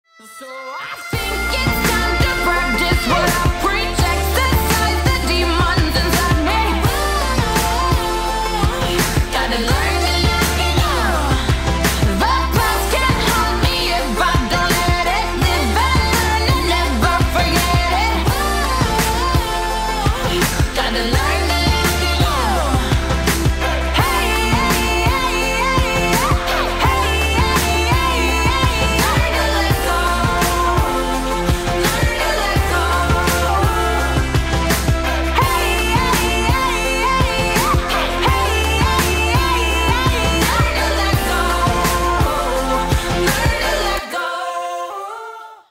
поп
женский вокал
dance
vocal